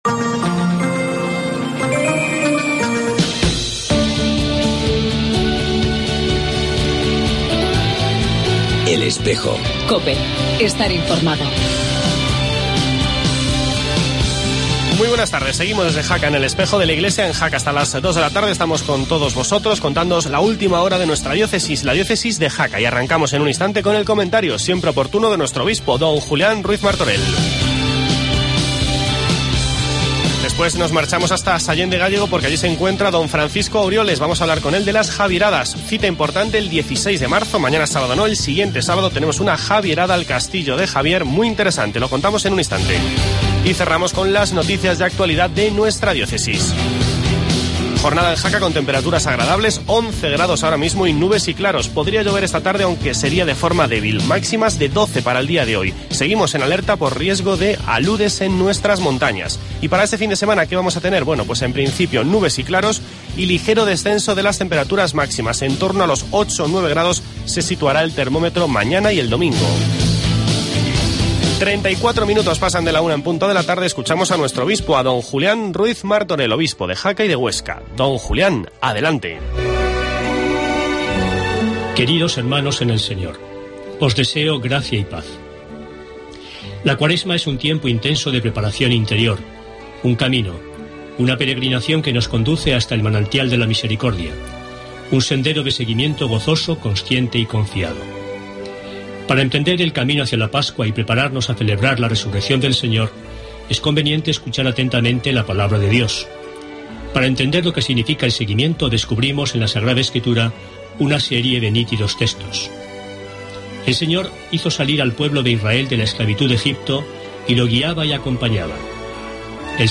Escuchamos el comentario de Don Julián Ruiz Martorell, obispo de Jaca y Huesca, y hablamos de las Javieradas.